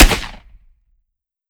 12ga Pump Shotgun - Gunshot A 005.wav